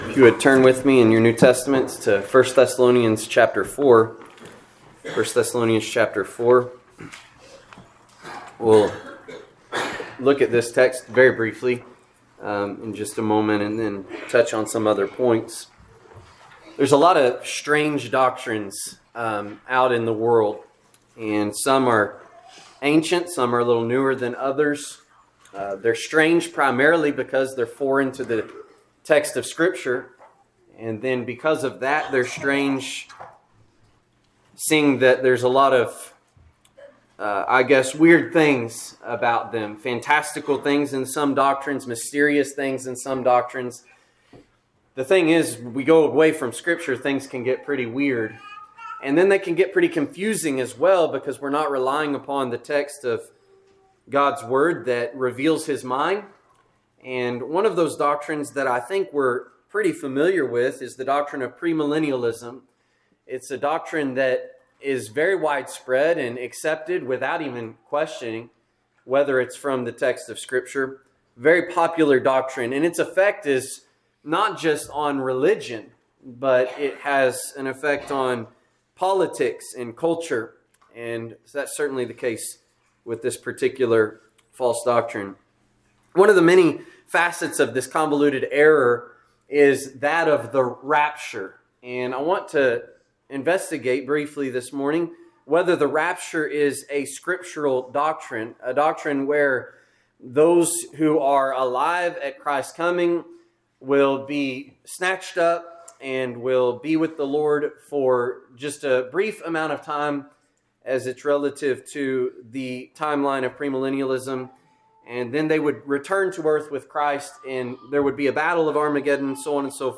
Seasonal Preaching Sermon Podcast